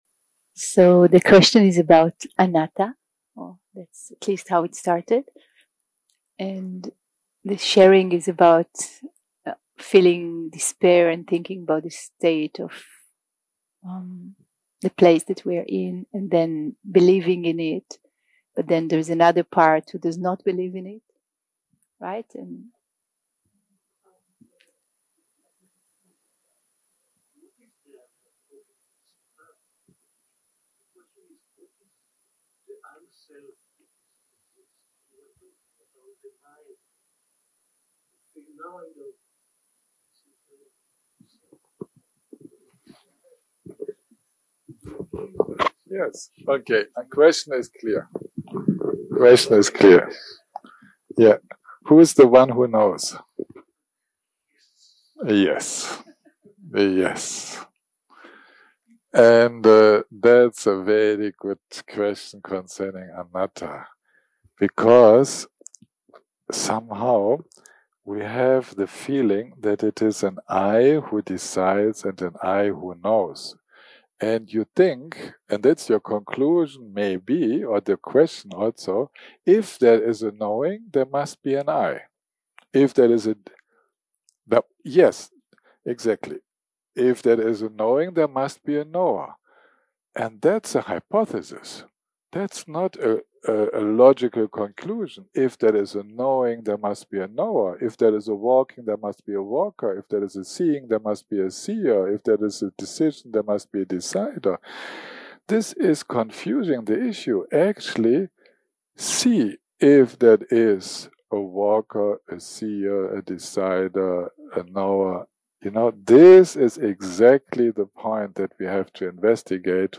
day 6 - recording 21 - Afternoon - Q&A.
Dharma type: Questions and Answers שפת ההקלטה